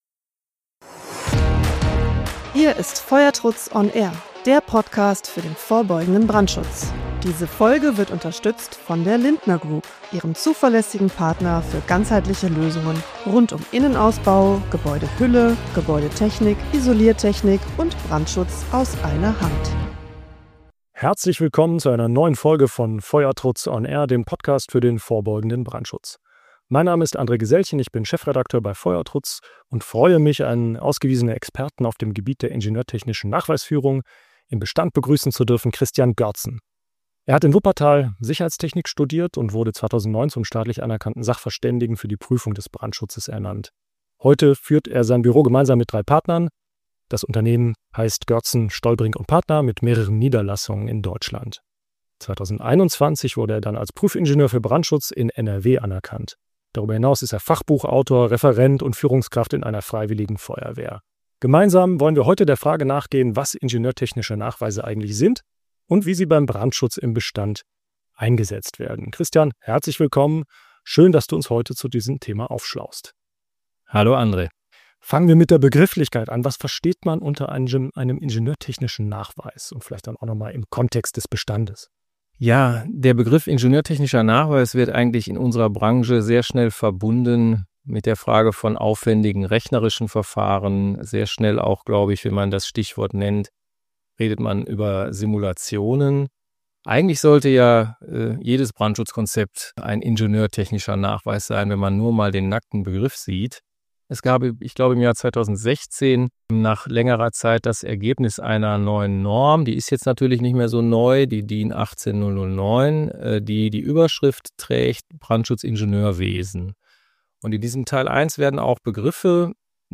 #9: Ingenieurtechnische Nachweise im Bestand | Im Gespräch